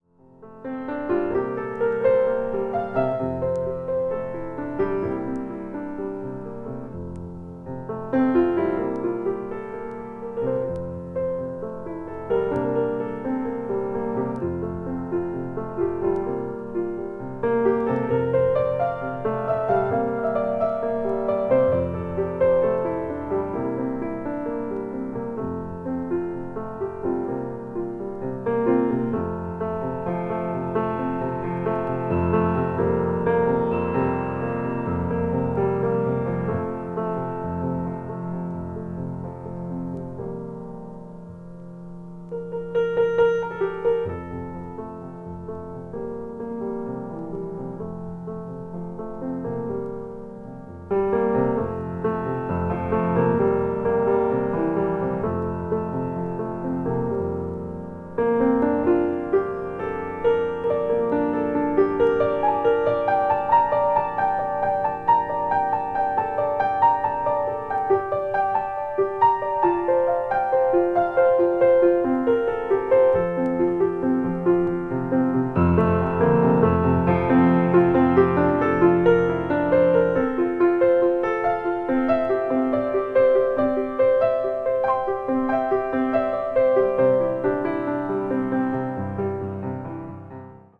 acoustic   new age   piano solo